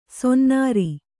♪ sonnāri